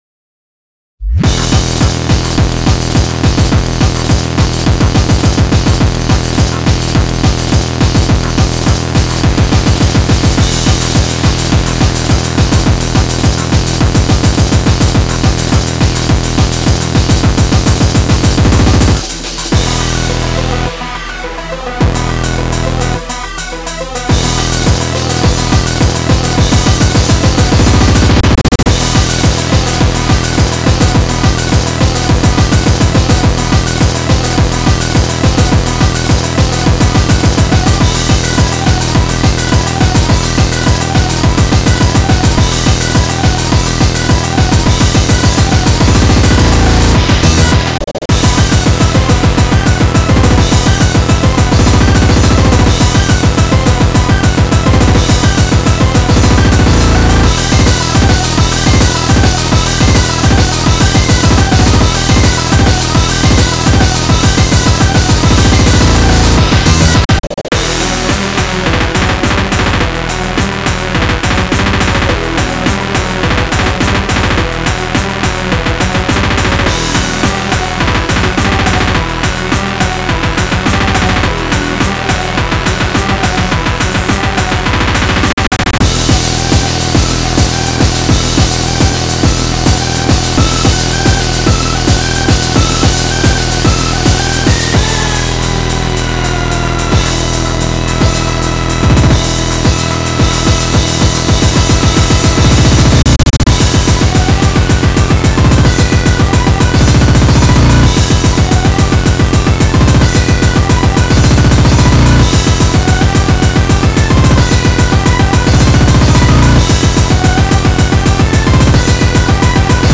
BPM105-880
Audio QualityPerfect (High Quality)
Genre: Chaotic Hardcore Crossover